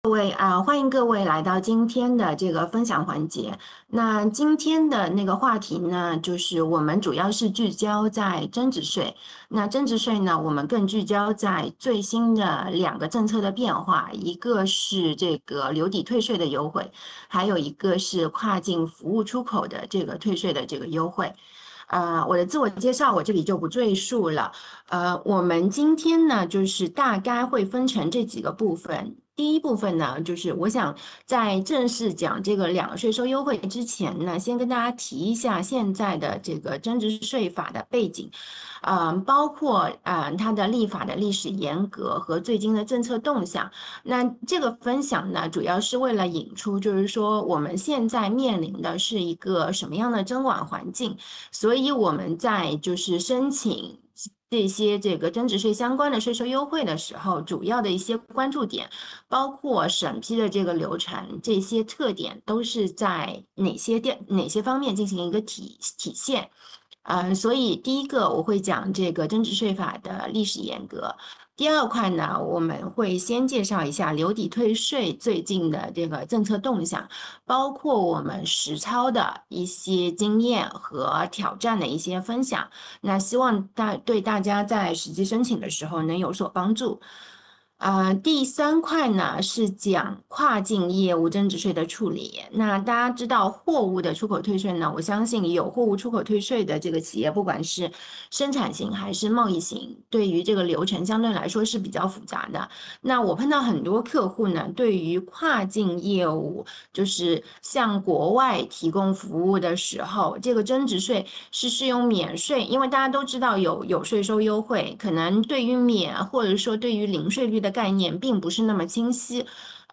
视频会议